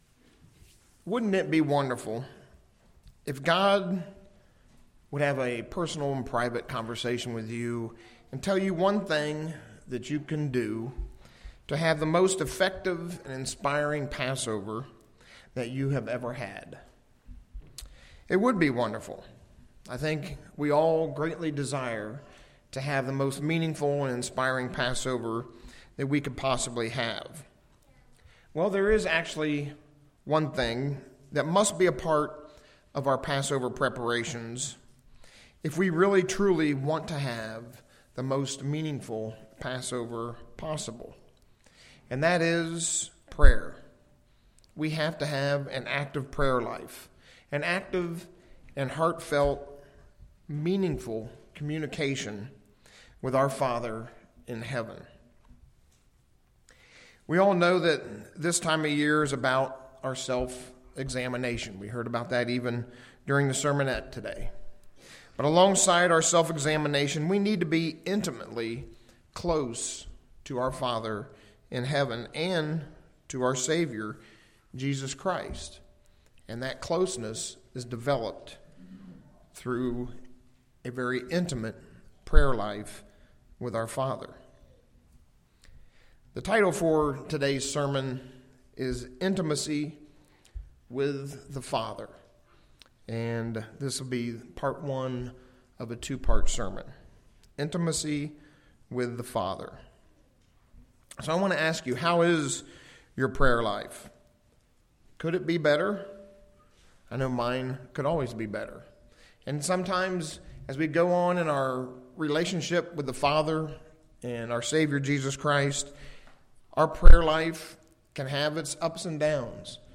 One way is through prayer. This sermon covers important aspects of our prayer life to obtain a deeper intimacy between us and our Father in heaven.